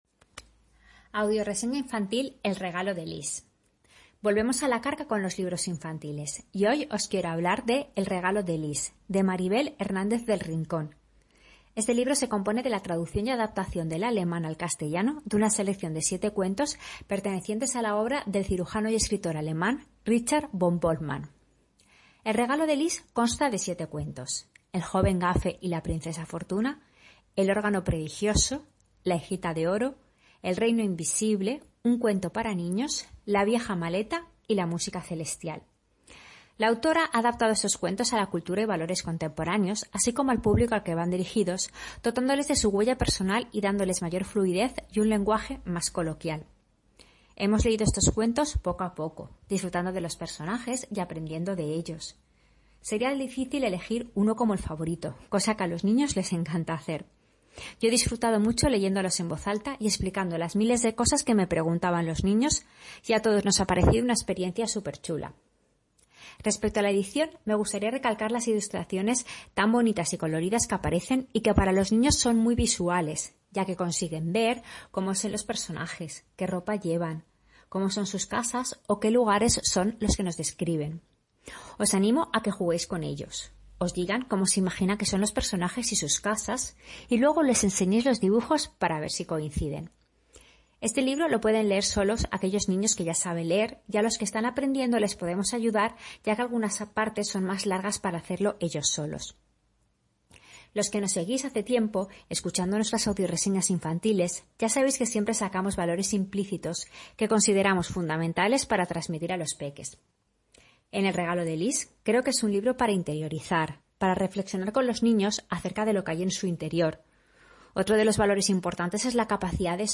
Audio reseña “El regalo de Lis”